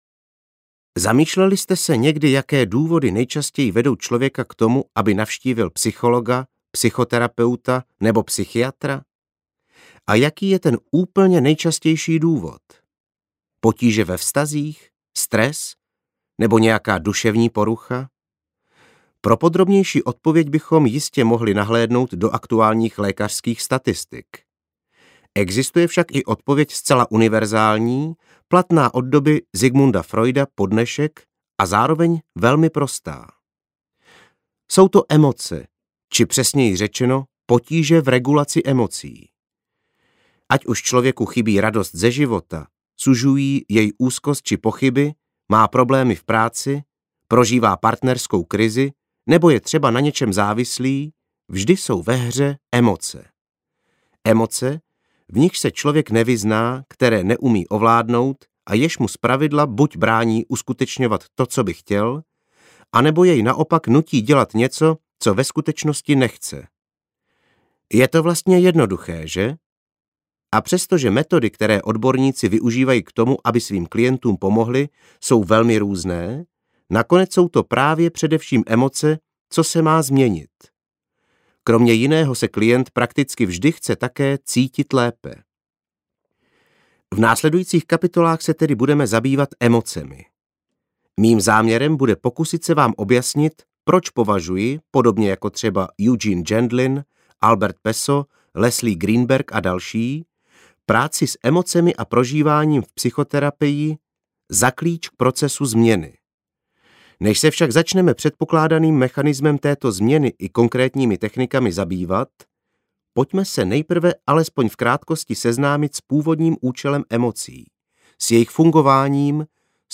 Ukázka z knihy
vsimavost-a-soucit-se-sebou-audiokniha